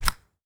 Golf Hit Wedge.wav